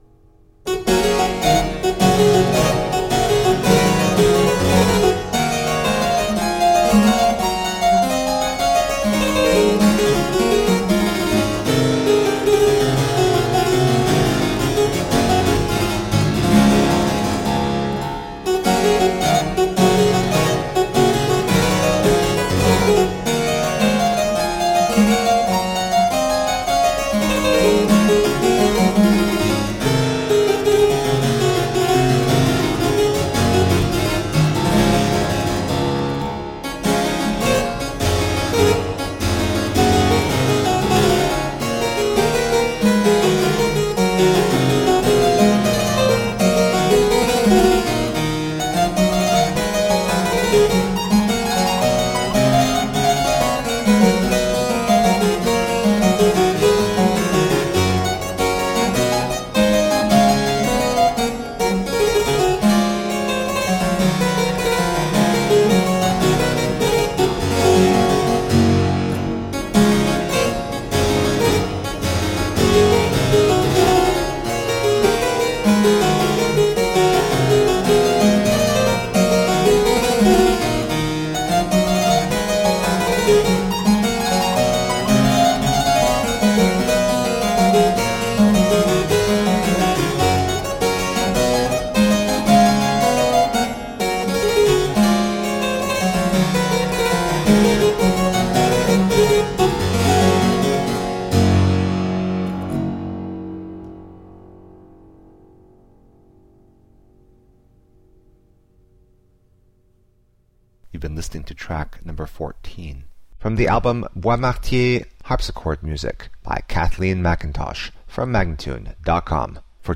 Classical, Baroque, Instrumental